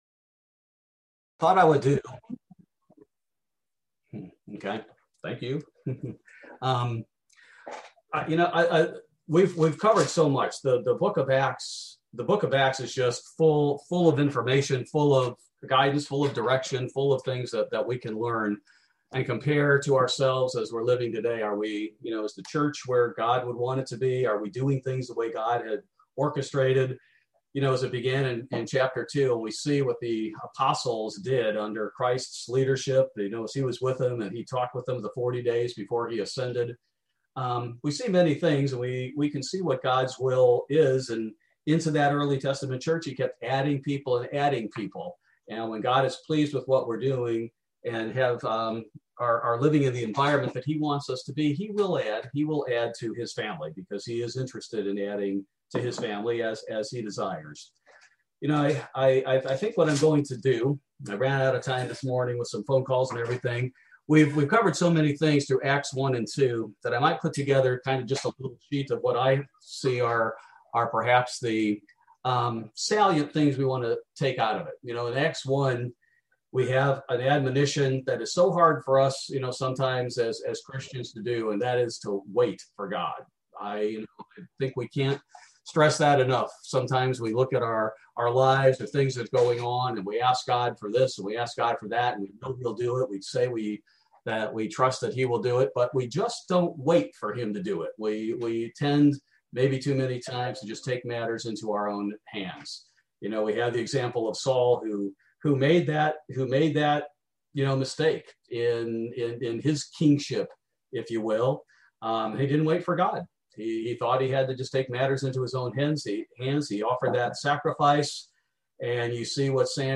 Bible Study: May 26, 2021